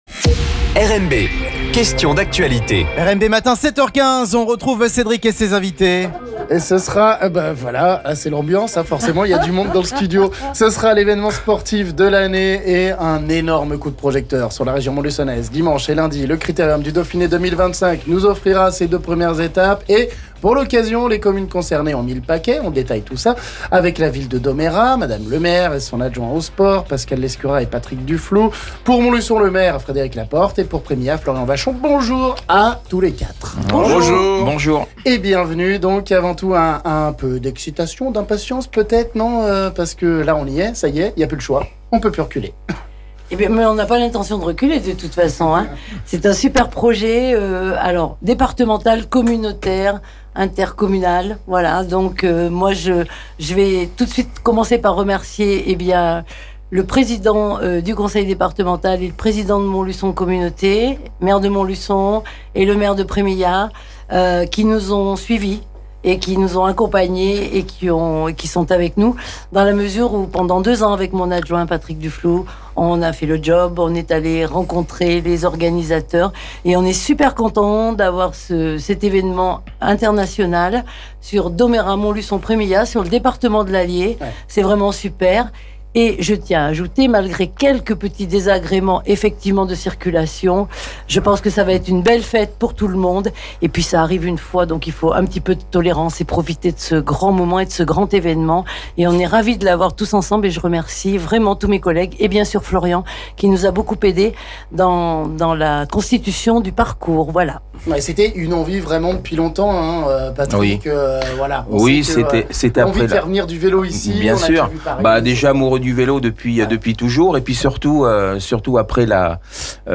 On détaille tout ça avec Mme le maire de Domérat et son adjoint aux sports Pascale Lescurat et Patrick Dufloux, le maire de Montluçon Frédéric Laporte et l'élu prémilhatois Florian Vachon...